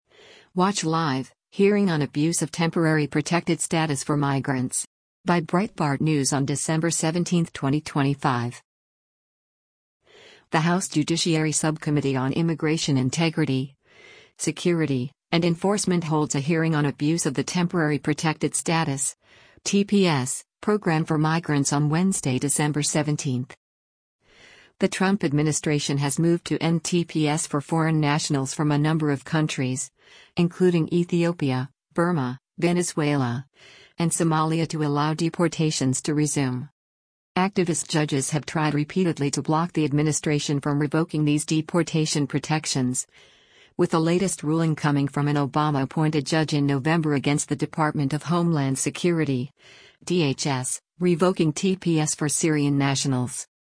The House Judiciary Subcommittee on Immigration Integrity, Security, and Enforcement holds a hearing on abuse of the temporary protected status (TPS) program for migrants on Wednesday, December 17.